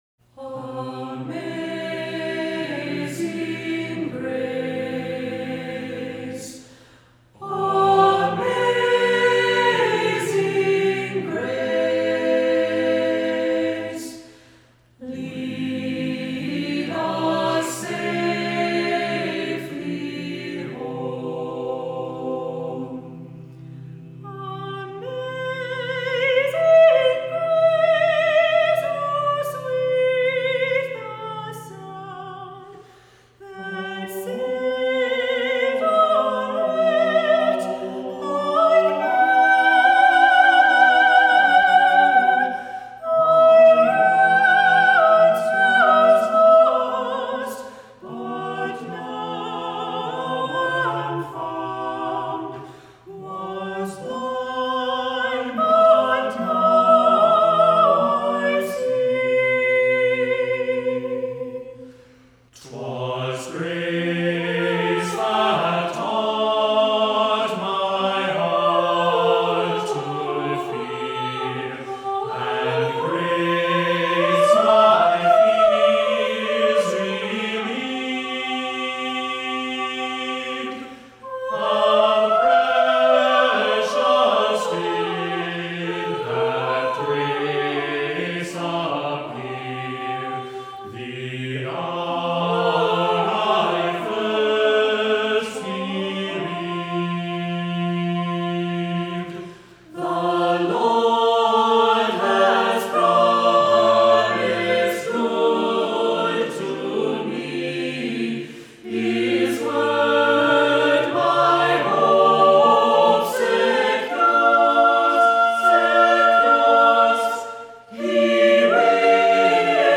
Voicing: SATB; Soprano solo; Descant; opt. Assembly